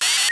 Hier ist eine Aufzeichnung eines Kommandos, das einen Rolladen schließt.